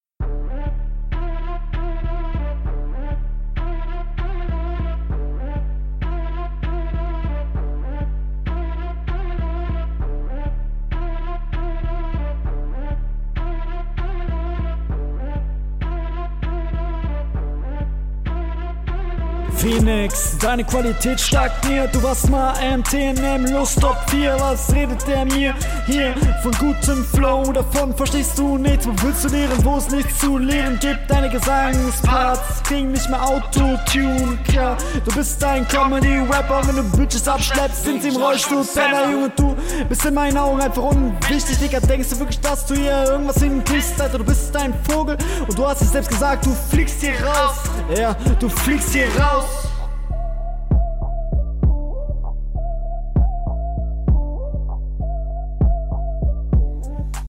Die doubles sind ne gute Idee, aber idk, vllt bissl zu laut.